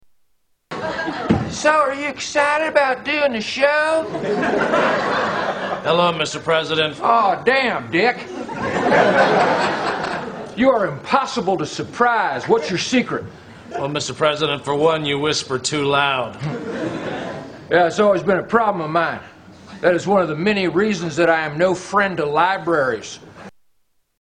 Tags: Comedians Darrell Hammond Darrell Hammond Impressions SNL Television